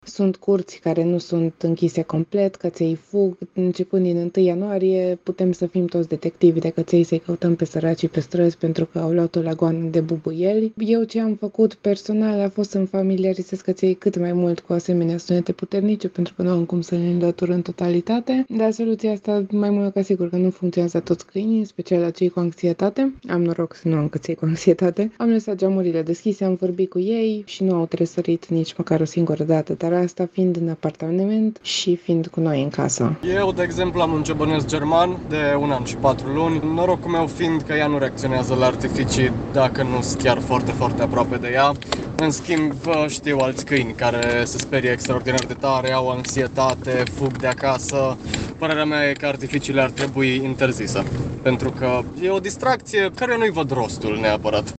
Nu toți propietarii de câini se confruntă cu aceste probleme, însă ele sunt reale și ar trebui să ne gândim la interzicerea artificiilor, spun doi târgumureșeni, proprietari de câini: